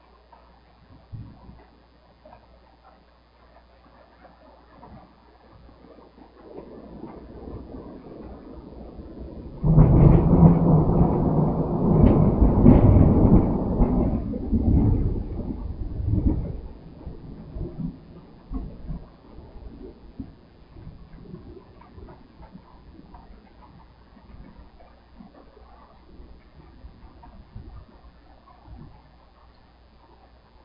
自然的声音 " 雷鸣般的室内
描述：记录从室内，雷鸣般的雷鸣。雷电雷雨天气雷雨滚滚雷声隆隆声
Tag: 闪电 天气 滚雷声 雷暴 雷暴 隆隆